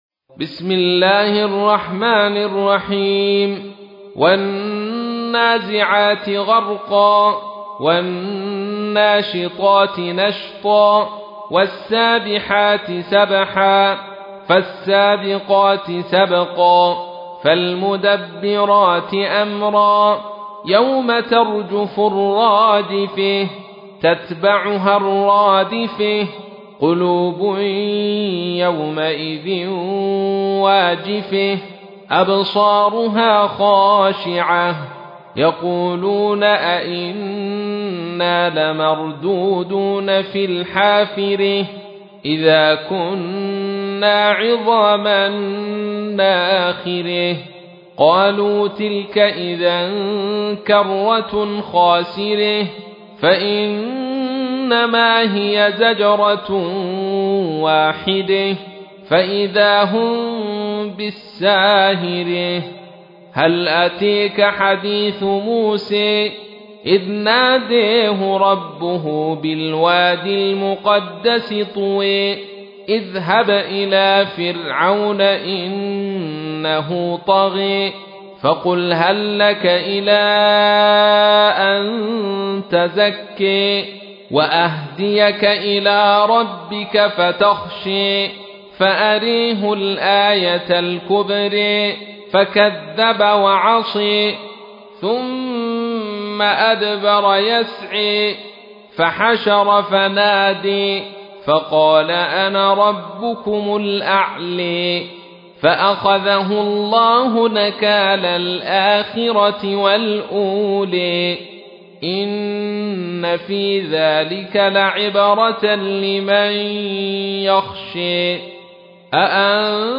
تحميل : 79. سورة النازعات / القارئ عبد الرشيد صوفي / القرآن الكريم / موقع يا حسين